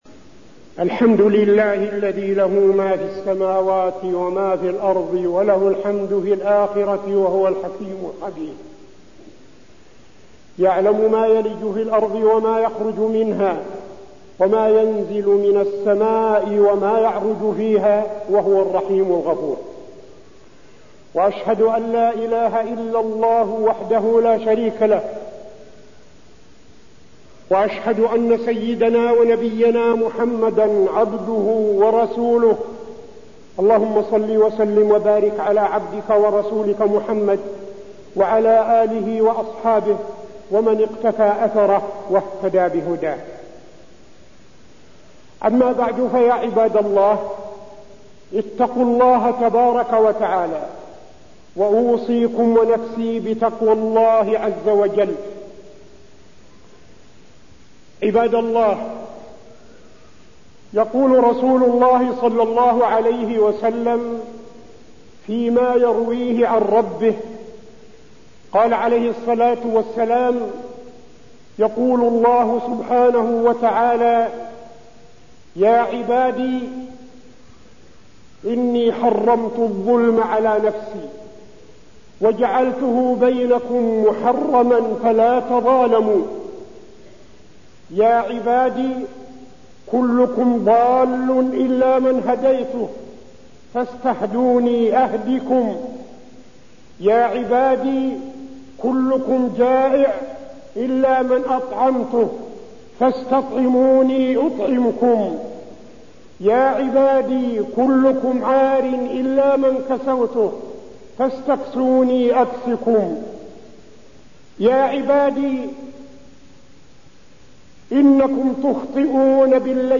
تاريخ النشر ٧ جمادى الأولى ١٤٠٦ هـ المكان: المسجد النبوي الشيخ: فضيلة الشيخ عبدالعزيز بن صالح فضيلة الشيخ عبدالعزيز بن صالح يا عبادي إني حرمت الظلم